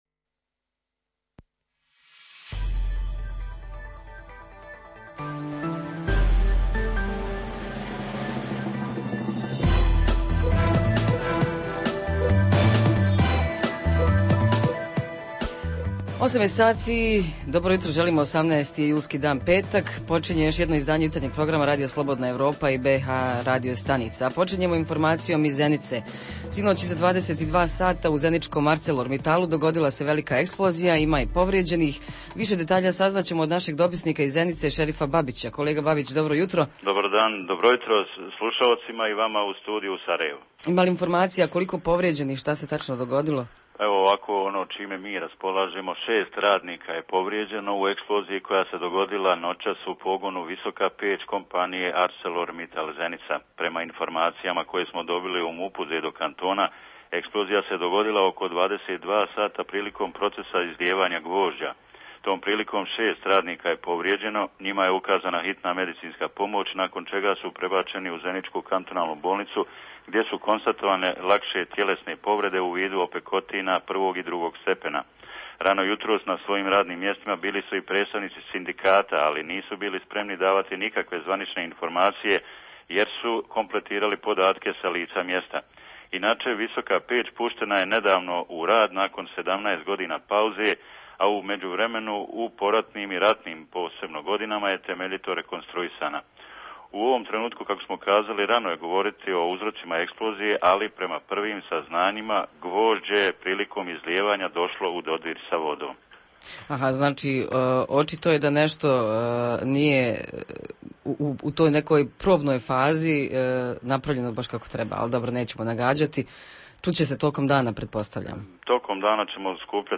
Redovni sadržaji jutarnjeg programa za BiH su i vijesti i muzika.